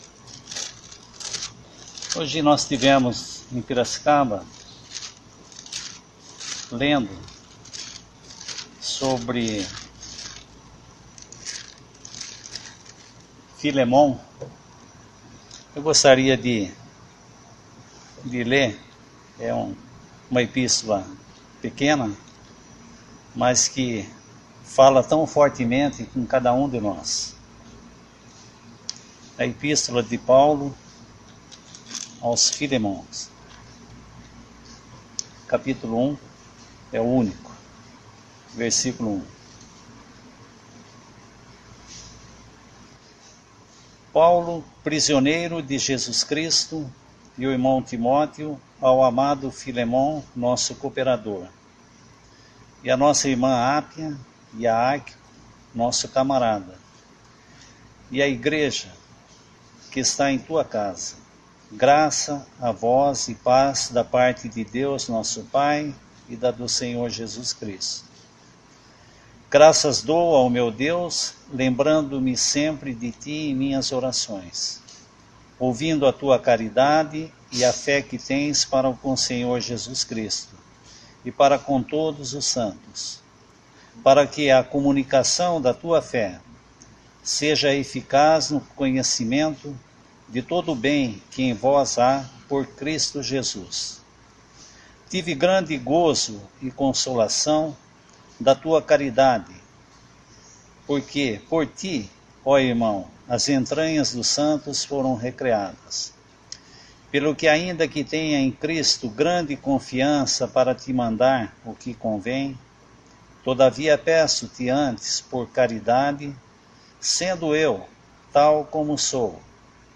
Arquivos em áudio com pregações do evangelho.